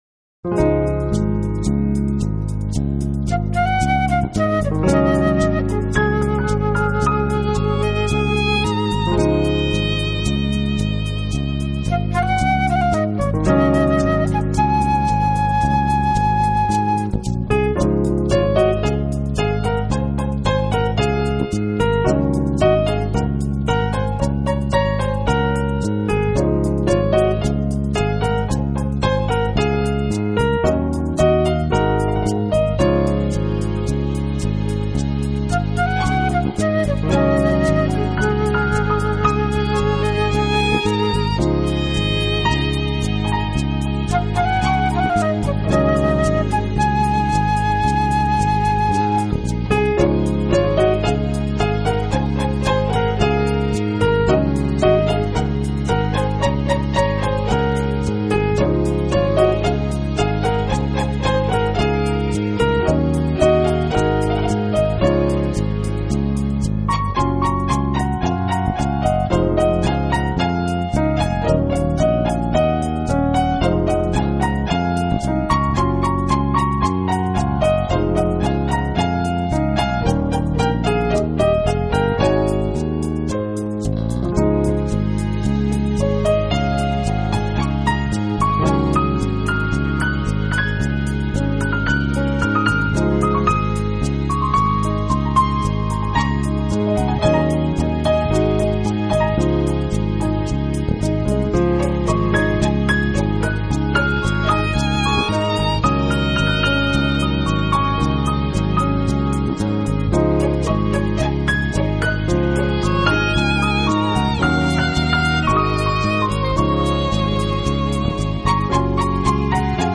【爵士专辑】